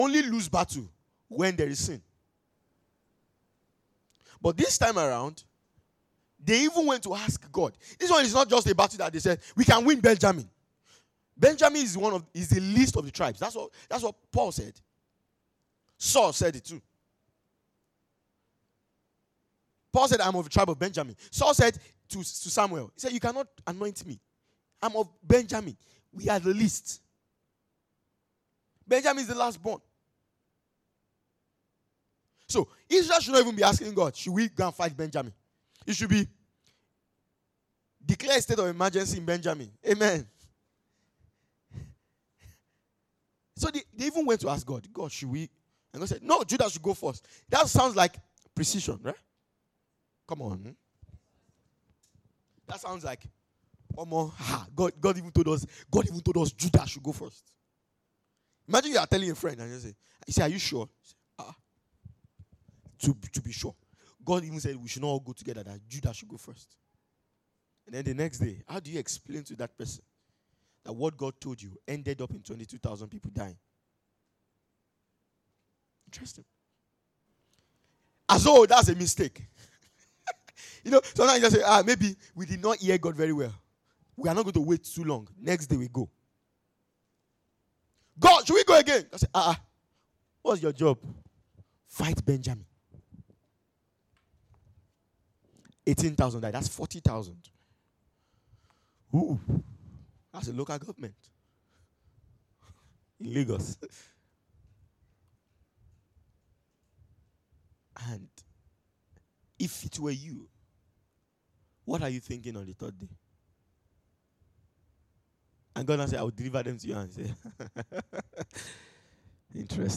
2025 Glorious House Church Teachings.